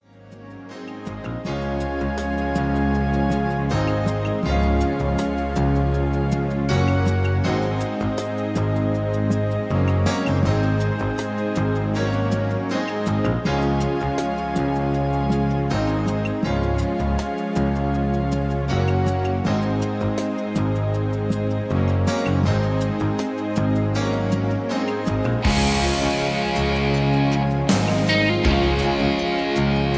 (Key-Db) Karaoke MP3 Backing Tracks